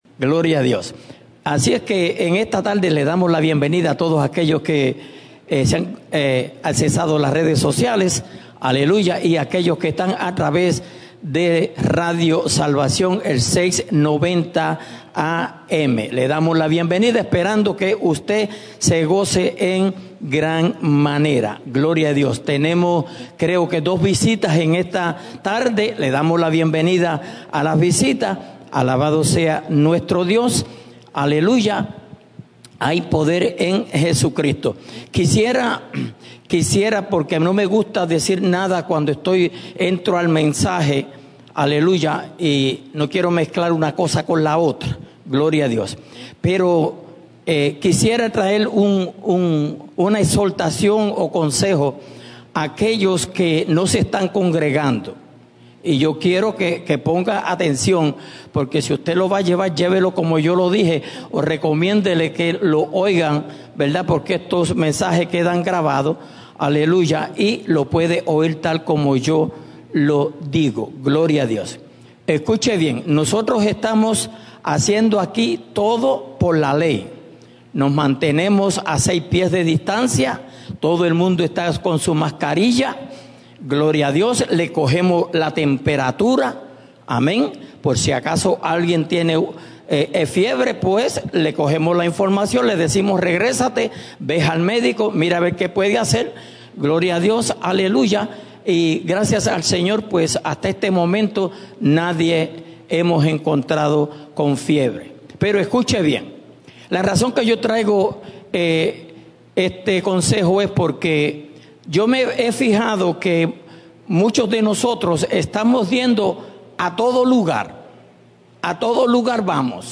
Mensaje